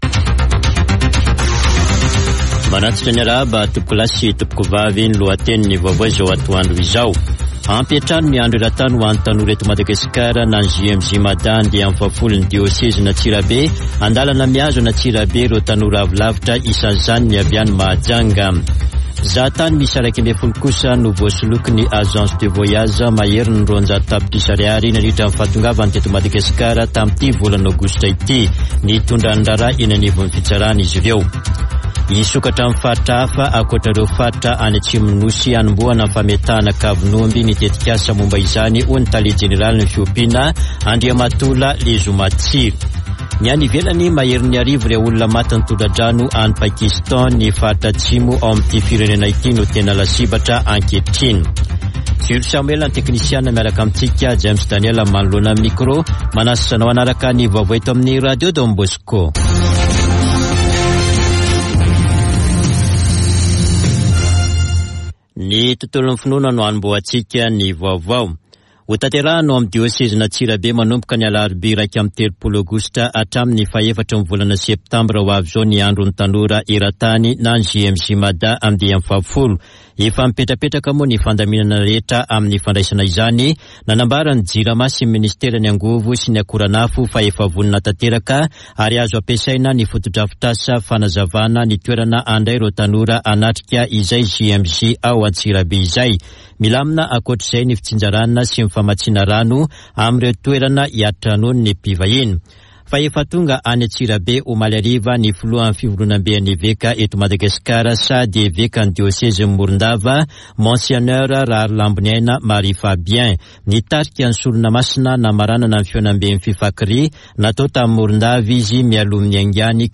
[Vaovao antoandro] Alatsinainy 29 aogositra 2022